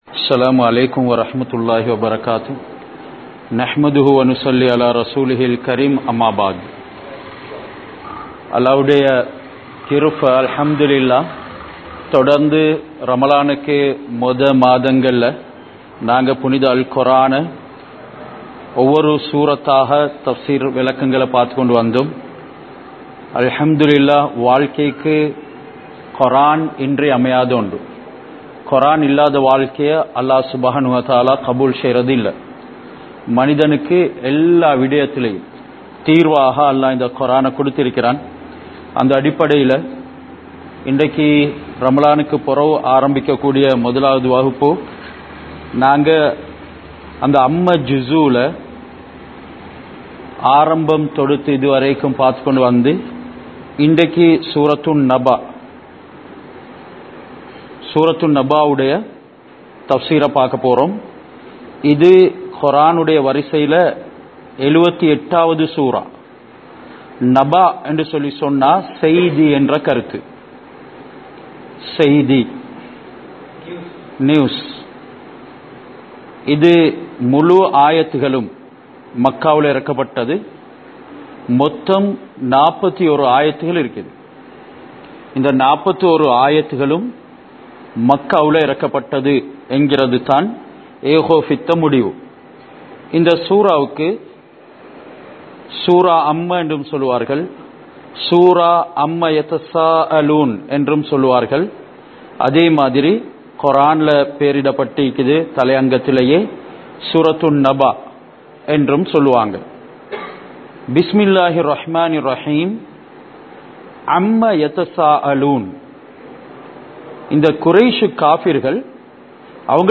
Surah An Naba (Thafseer Versus 1 - 18) | Audio Bayans | All Ceylon Muslim Youth Community | Addalaichenai